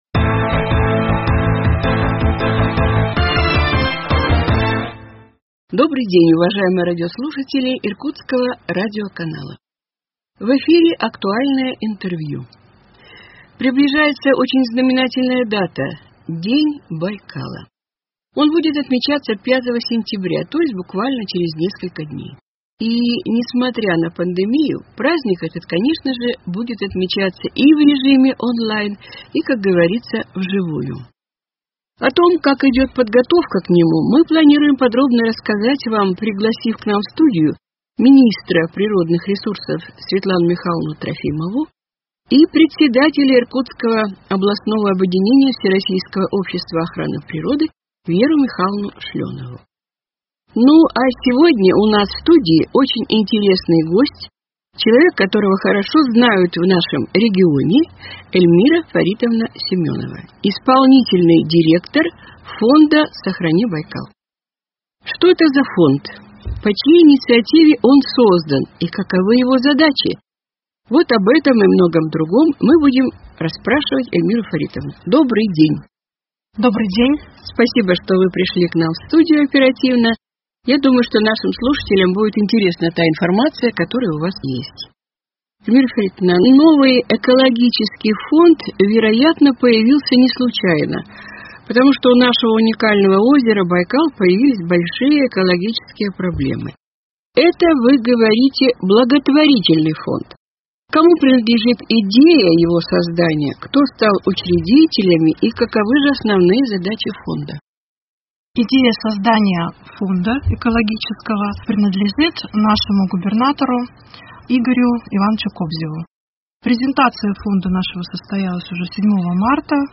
Актуальное интервью: Фонд «Сохраним Байкал» 24.08.2021